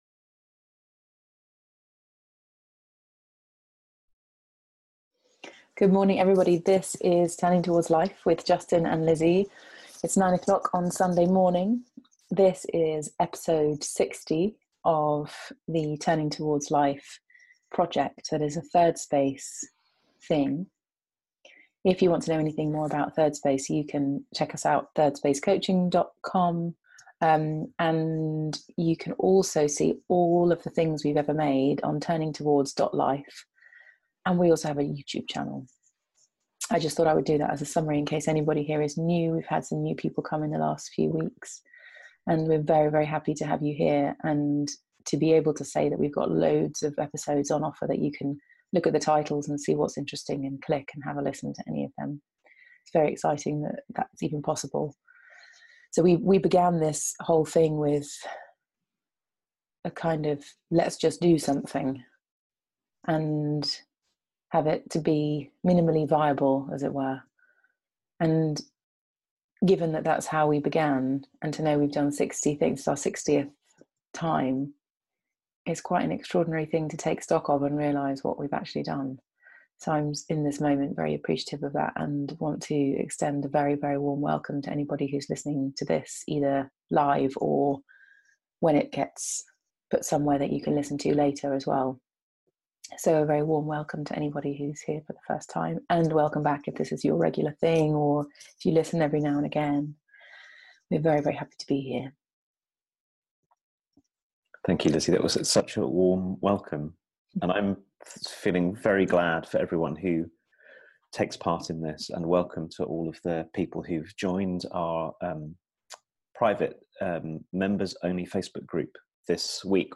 Along the way we have a laugh together about this crazy being-a-person we all find ourselves in the middle of, and we consider the role of others in helping each of us find a way to be in life that's less punishing and more dignified. Turning Towards Life is a weekly live 30 minute conversation hosted by Thirdspace